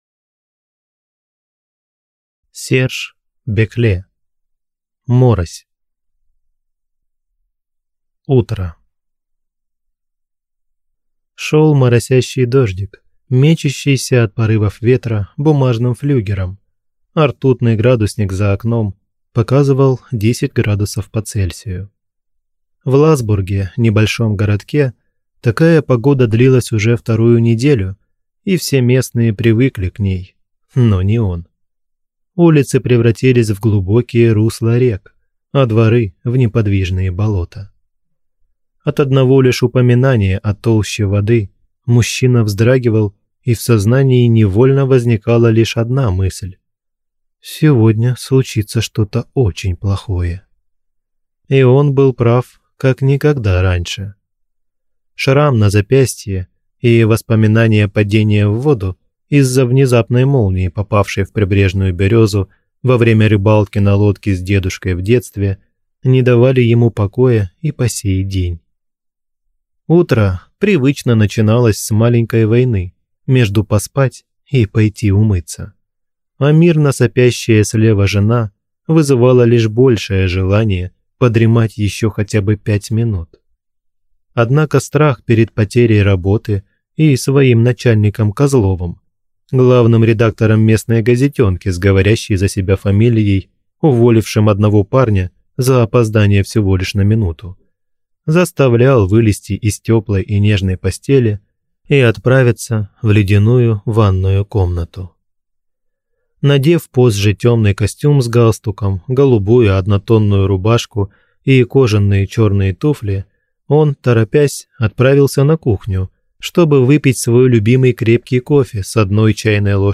Aудиокнига Морось